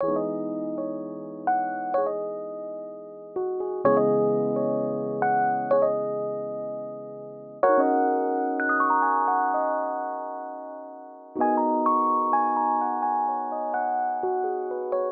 14 rhodes A.wav